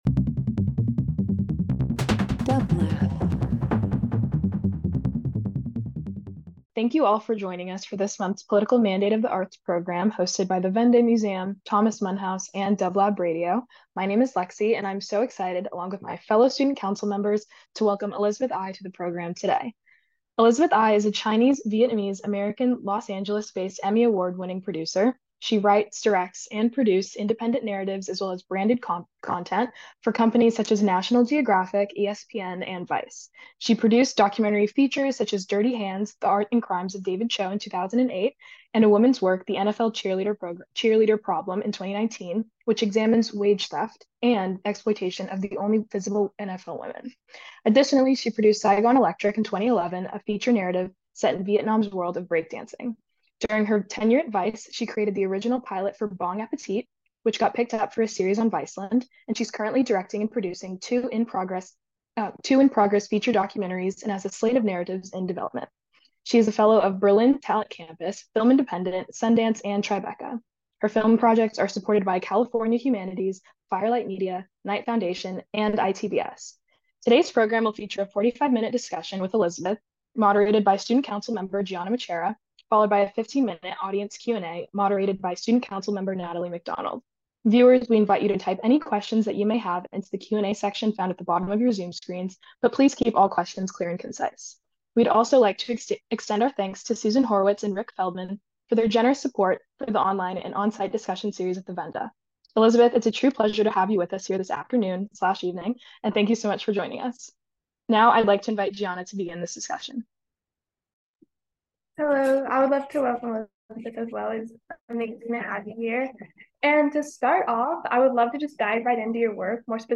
Interview Talk Show
The Student Council consists of a team of highly engaged, talented, and diverse high school, undergraduate, and graduate students who invite prominent guest speakers to discuss topics relating to art, culture, politics, and society.